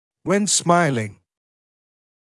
[wen ‘smaɪlɪŋ][уэн ‘смайлин]при улыбке